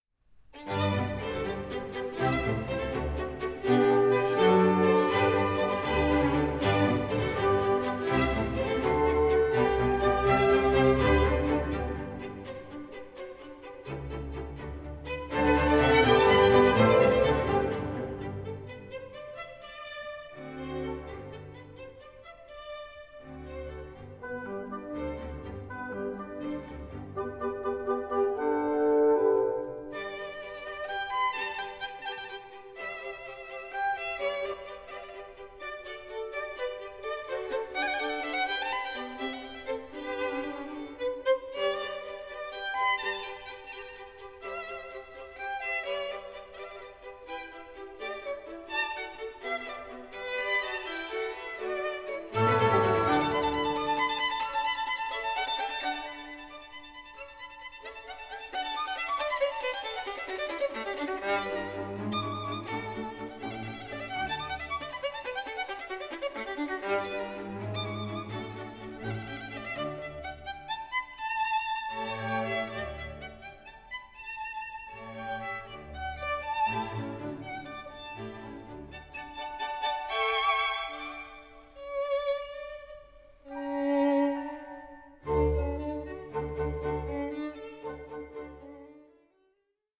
(48/24, 88/24, 96/24) Stereo  14,99 Select